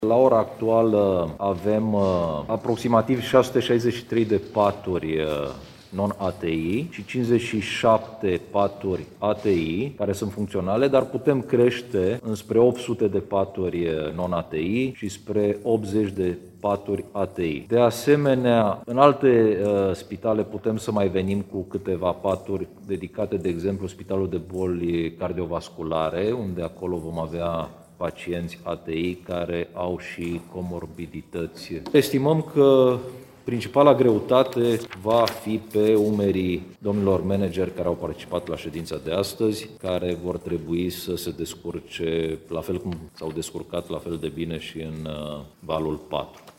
În urma discuțiilor, s-a stabilit ca numărul paturilor din unitățile medicale, atât cele dedicate paciențior non-ATI, cât și celor care au nevoie de terapie intensivă, va fi suplimentat, dacă va fi nevoie, spune subprefectul Ovidiu Drăgănescu.